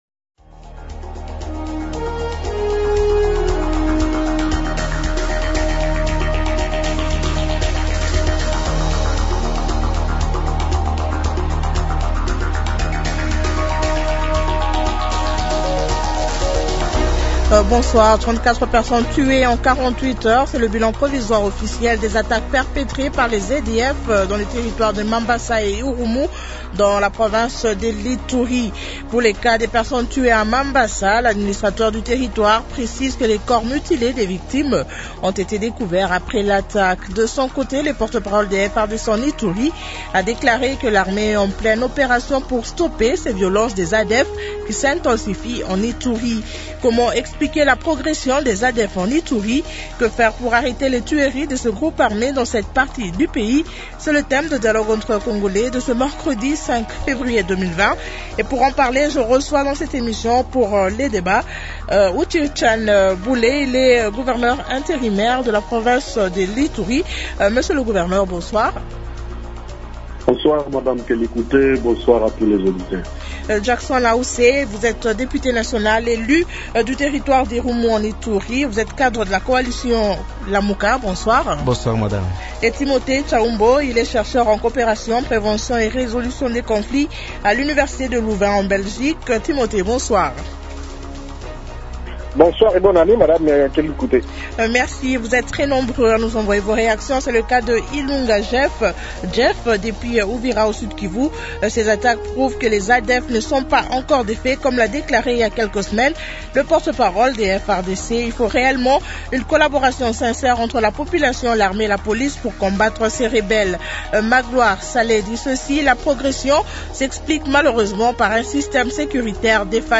Comment expliquer la progression des ADF en Ituri ? Que faire pour arrêter les tueries de ce groupe armé dans cette partie du pays ? Invités : -Uchirchane Bule, Gouverneur intérimaire de la province de l’ITURI. -Jackson Ause Asingoto, Député national élu du territoire d’IRUMU en ITURI.